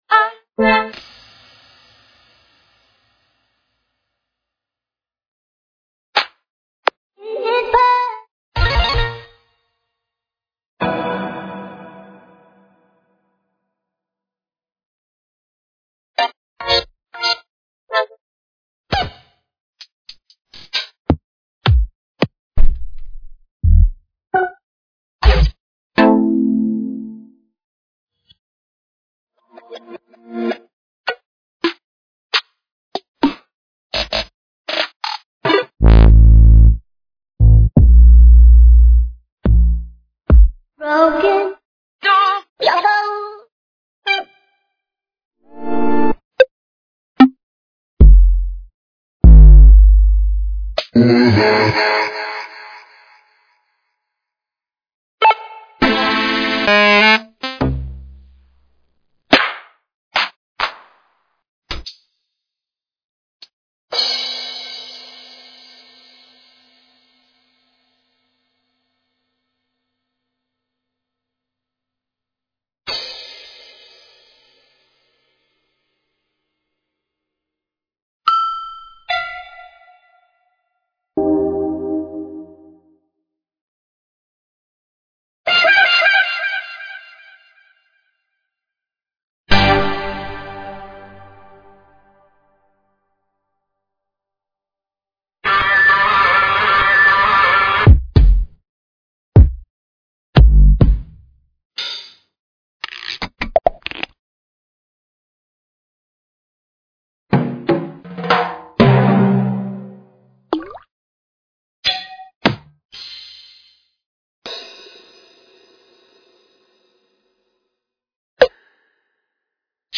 This brand new pack is a mixture of Chill- and Future Trap.
100 best quality Samples (24bit, 44.1kHz, Stereo)